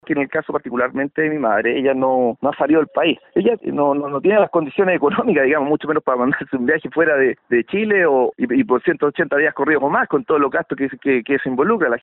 En conversación con La Radio, considera insólito que en estos tiempos aún falle la información que se supone está en línea.